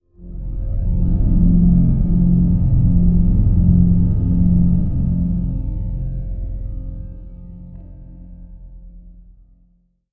cinematic_LowDrone1.wav